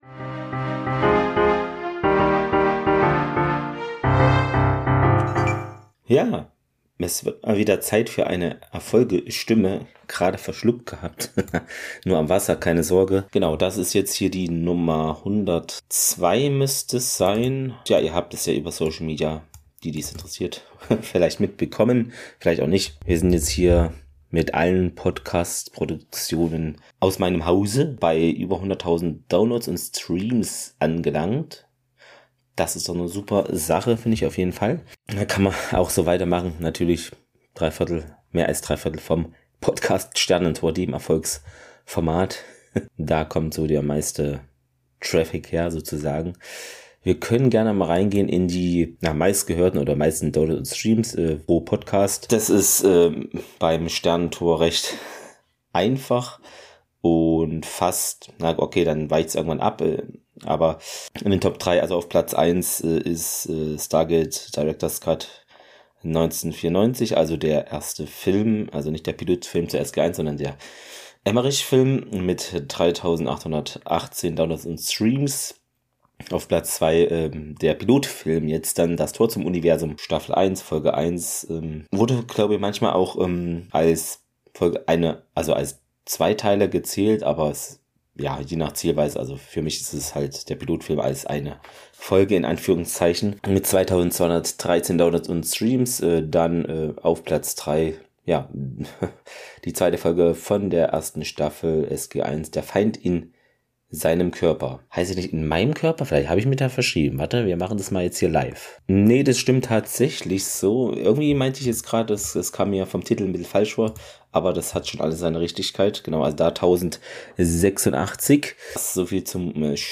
Nerdige (Selbst)Gespräche, meistens über Medienerzeugnisse, die mich aktuell beschäftigen.